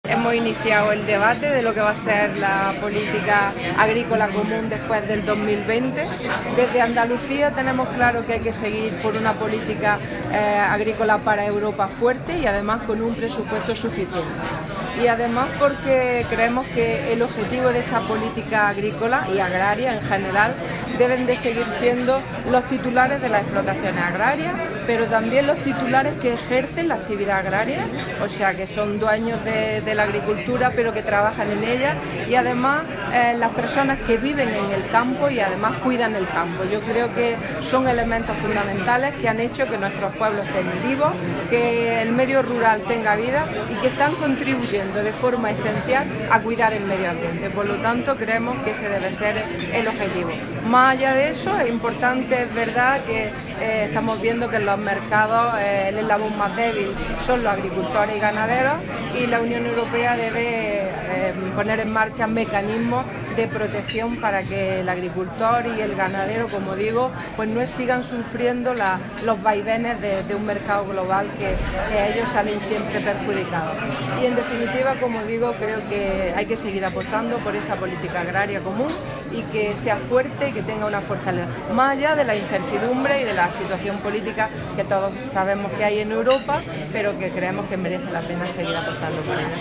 La consejera de Agricultura ha intervenido en Madrid en la conferencia que abre el debate sobre la reforma de la Política Agrícola Común post 2020
Declaraciones consejera PAC post 2020